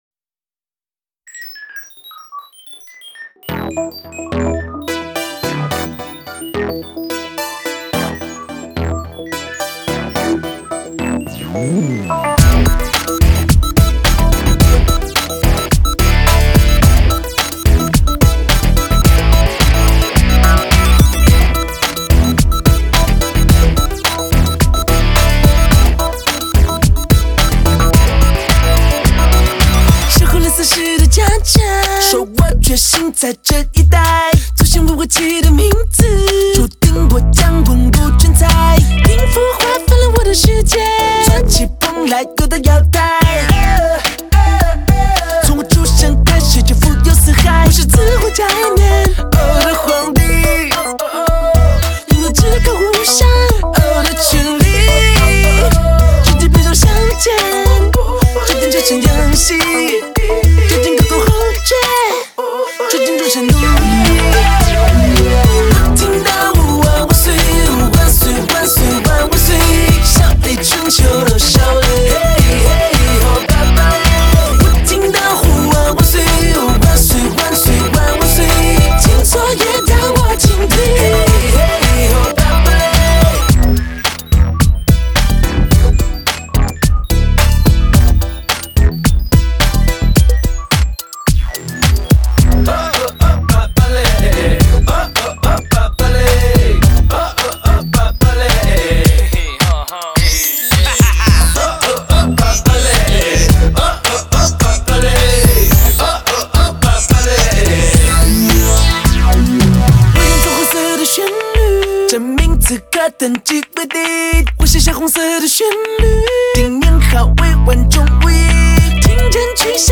没有之前歌曲 那么复杂的和弦转换，反而有一种别样的粗犷气度， 真有所谓磅礴奇宕的感觉。